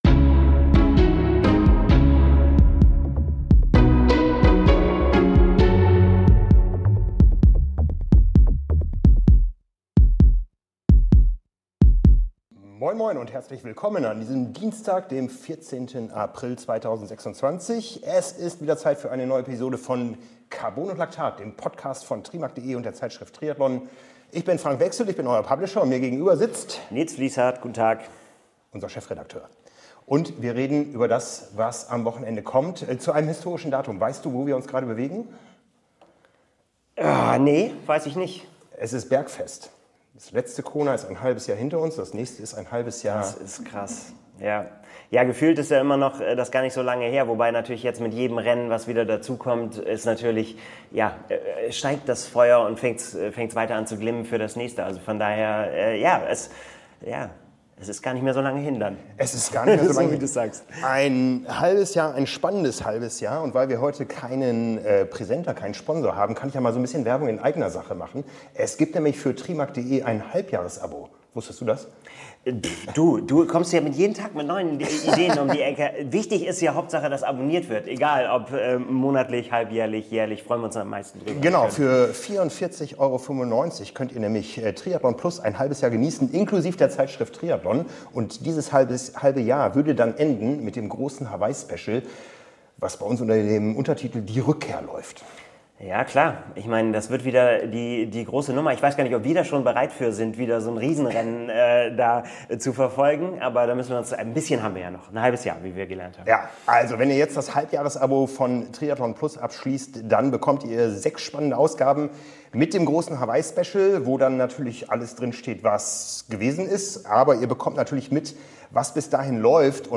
Hier ist die Episode aus dem Trainingslager auf Mallorca.